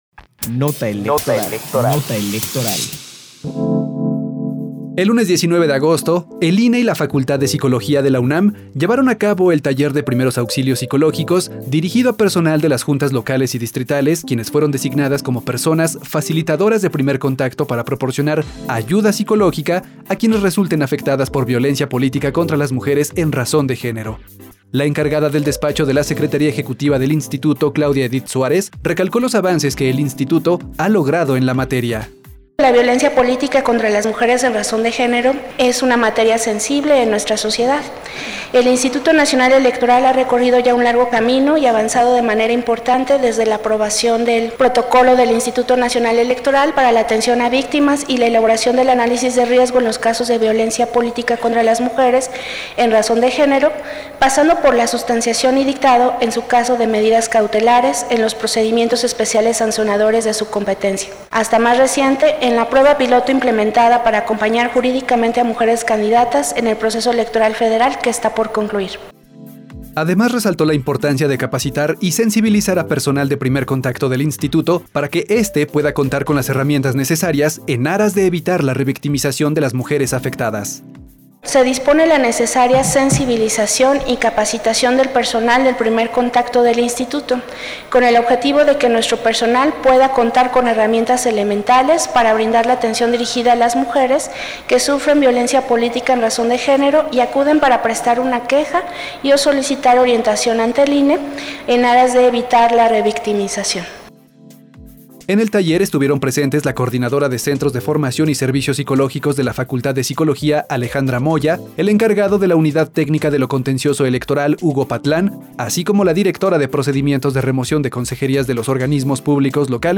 Nota de audio sobre el Taller de Primeros Auxilios Psicológicos, 20 de agosto de 2024